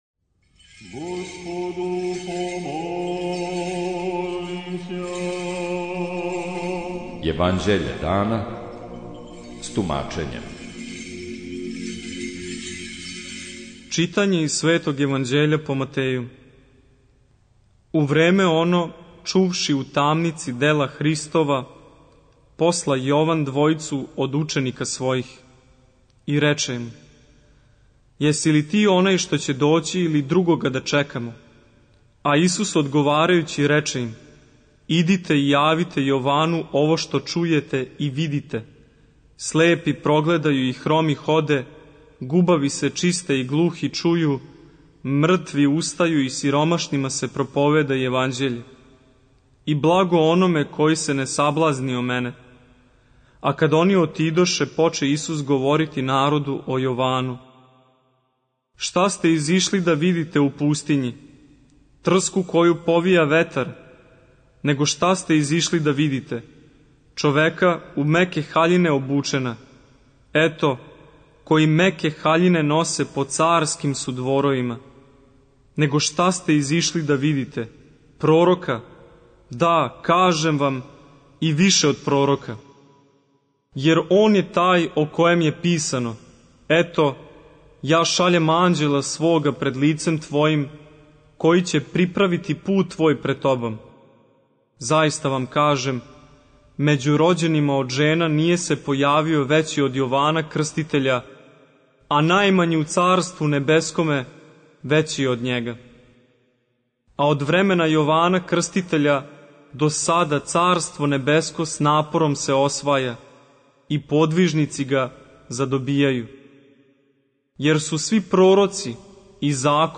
Читање Светог Јеванђеља по Луки за дан 22.01.2026. Зачало 13.